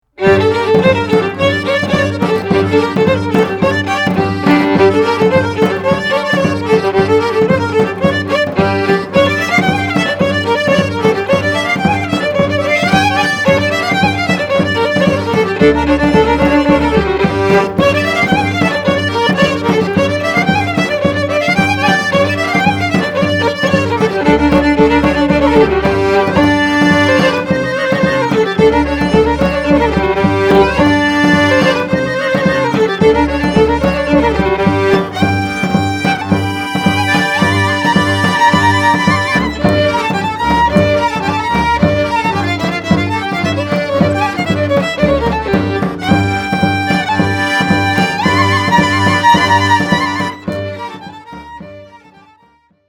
Recorded at Bay Records, Berkeley, CA, May 2003
Genre: Klezmer.
violin, accordion, bass, baraban (8,11)   2:00